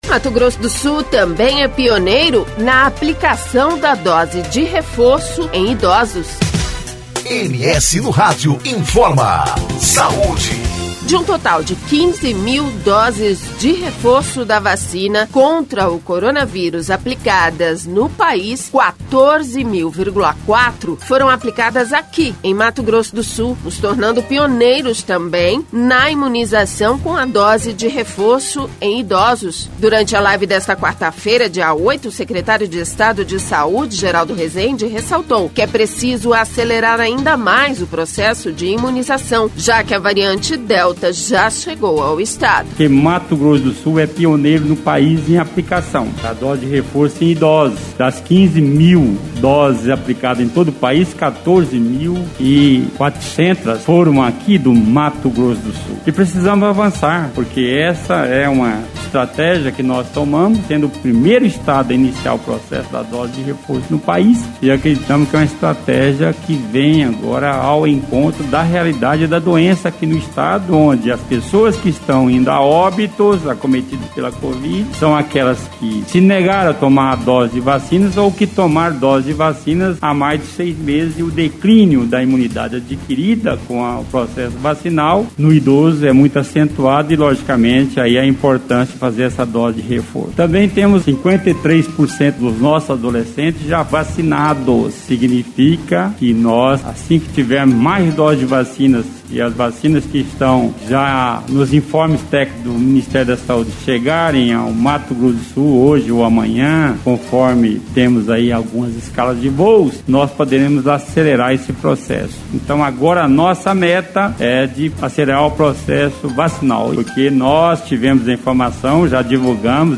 Durante a live desta quarta-feira, dia 08, o secretário de estado de saúde, Geraldo Resende, ressaltou que é preciso acelerar ainda mais o processo de imunização, já que a variante Delta já chegou ao Estado.